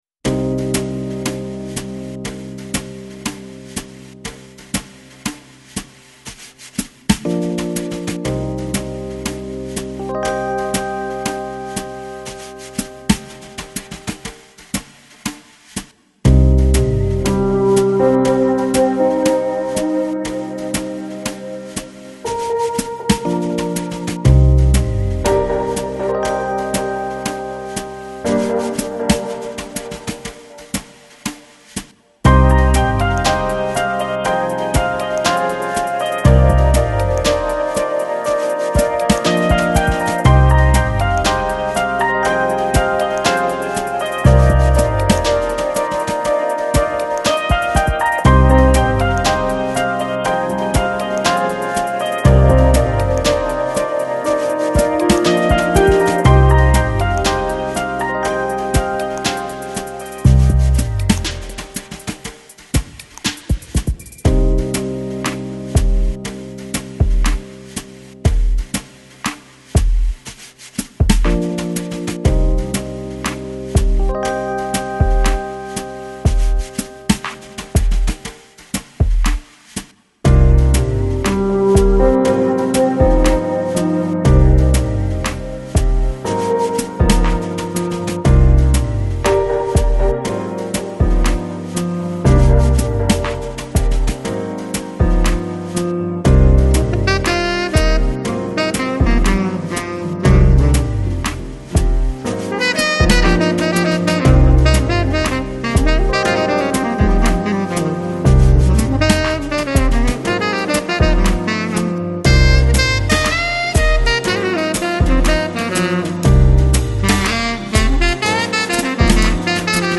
Жанр: Lounge, Chillout, Downtempo, Nu Jazz